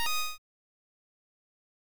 pick up coin.wav